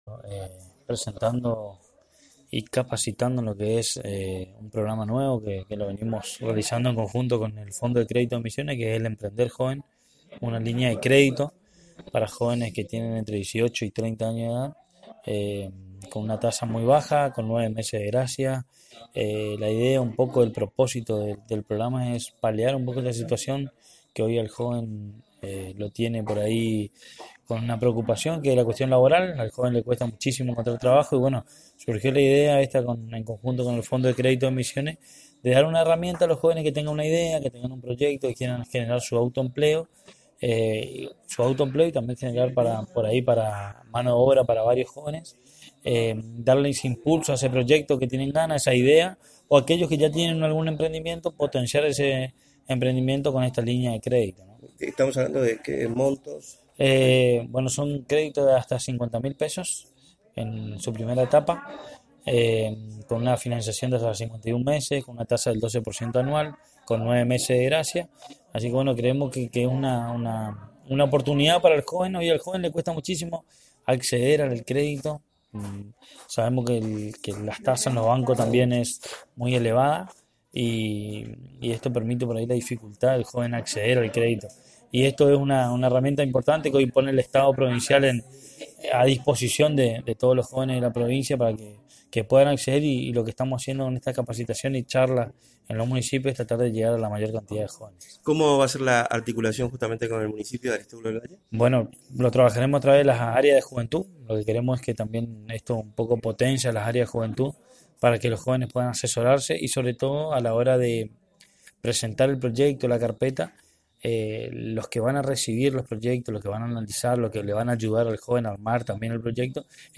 Audio: Pablo Nuñez – Subsecretario de Juventud